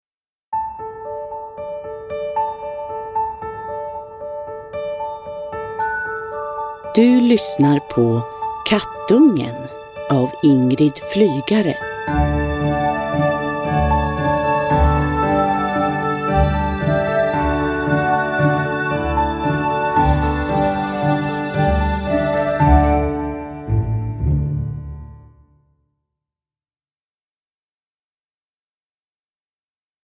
Kattungen – Ljudbok